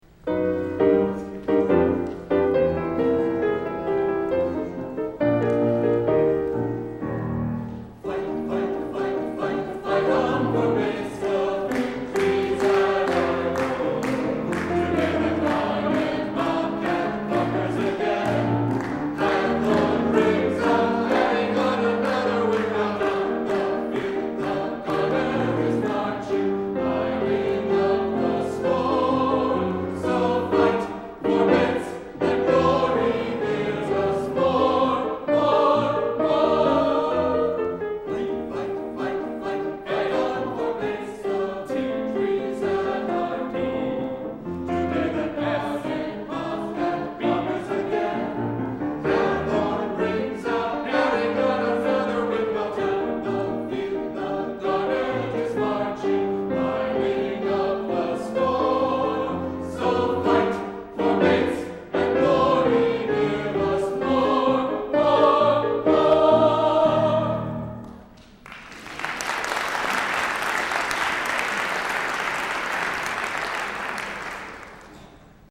The Bates orchestra and choir
fight-song-inauguration.mp3